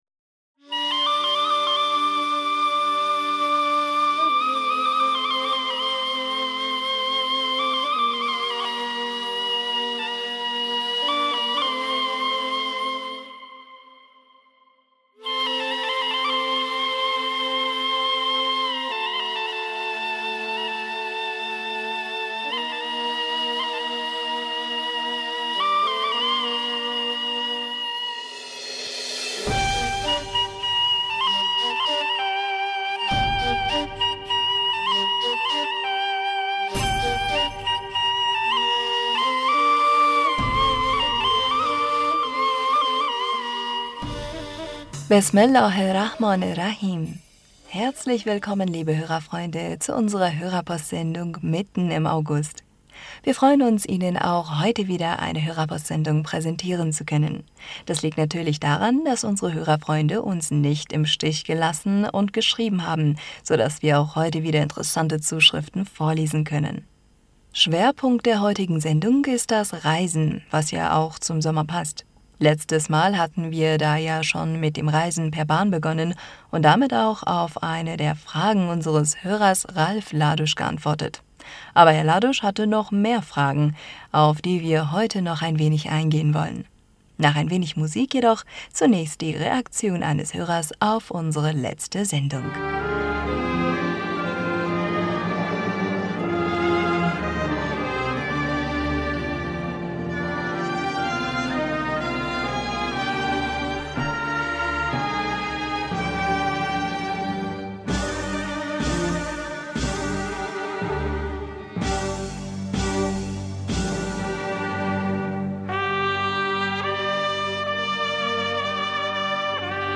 Hörerpostsendung am 15. August 2021